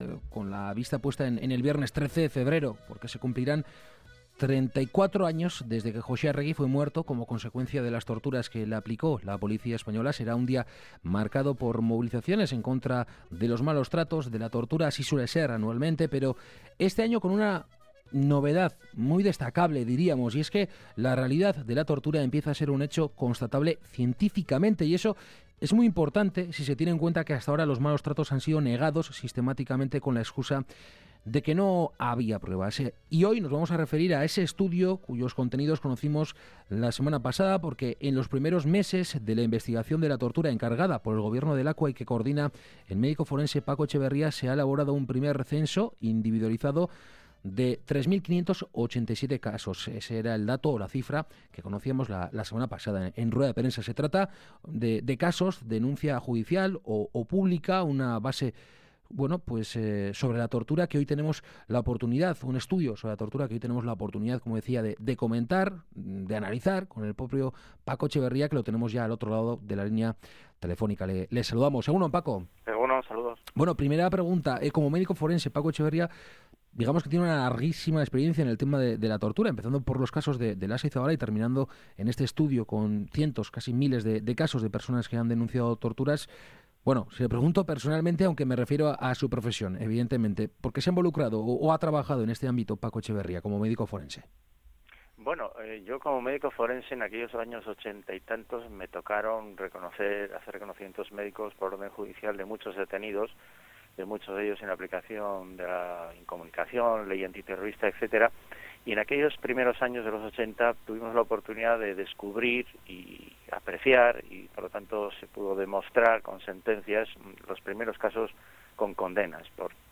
Esta mañana en Kalegorrian hemos entrevistado al médico forense